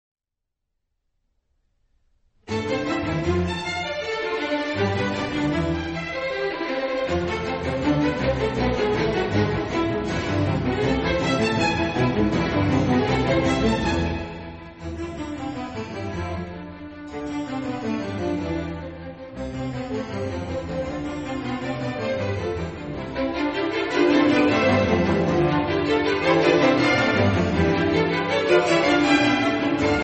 • registrazione sonora di musica